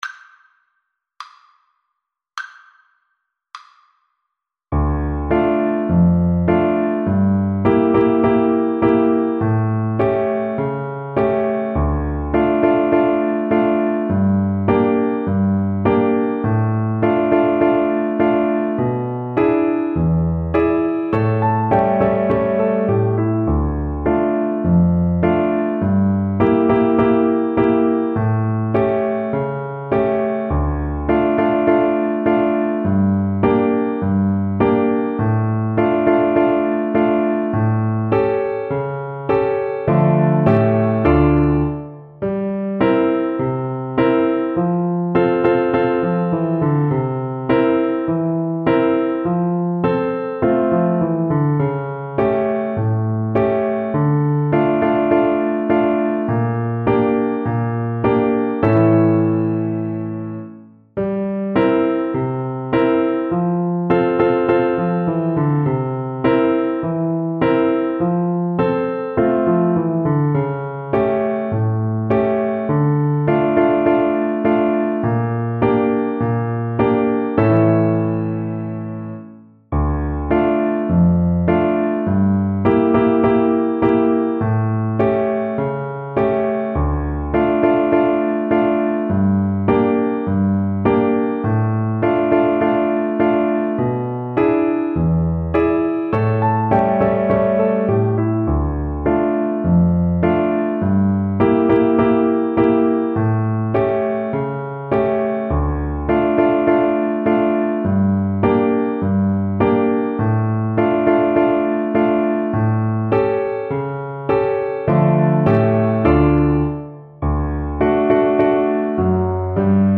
Classical Chiquinha Gonzaga Tamoio (Pas de quatre) French Horn version
French Horn
=c.80 Andante
D minor (Sounding Pitch) A minor (French Horn in F) (View more D minor Music for French Horn )
2/4 (View more 2/4 Music)
Classical (View more Classical French Horn Music)